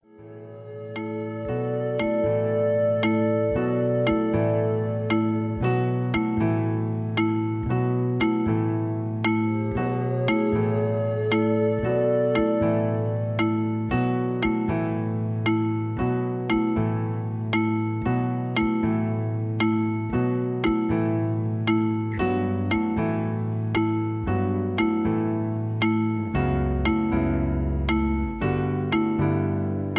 backingtracks, karaoke, sound tracks
backing tracks, rock